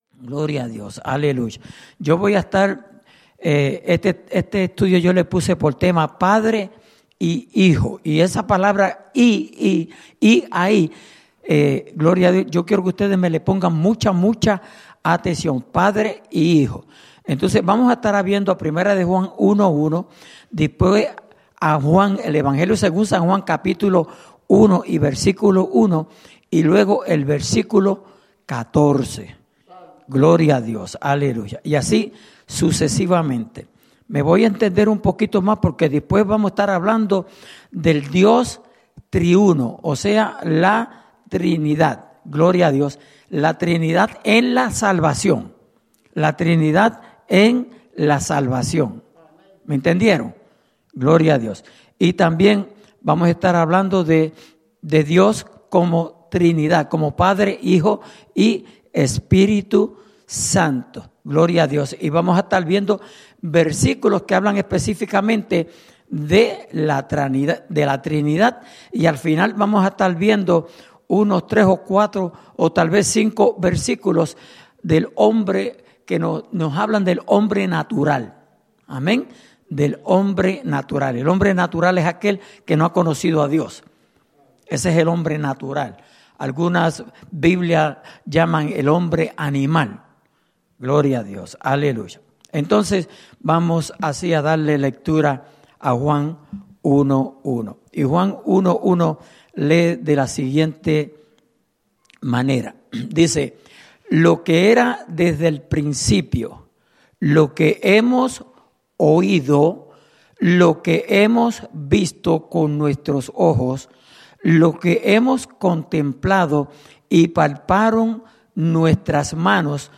Estudio Bíblico: Padre Y Hijo